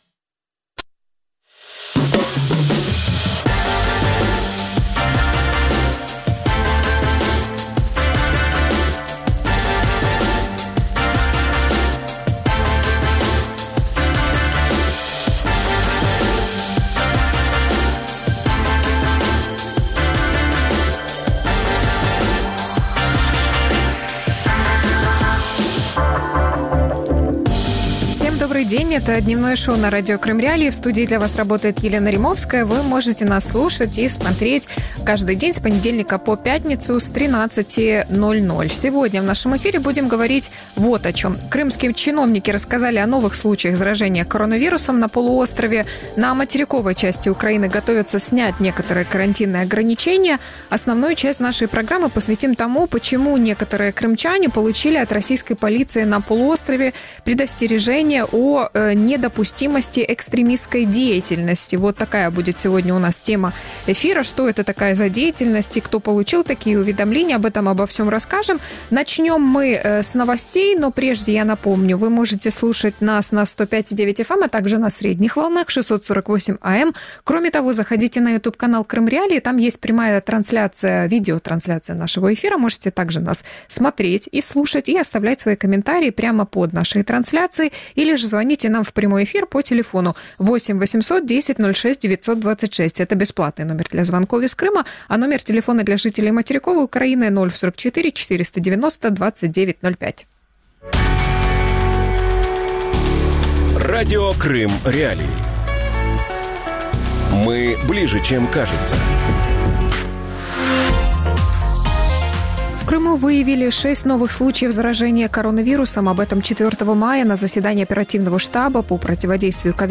В Крыму снова боятся «экстремистов» | Дневное ток-шоу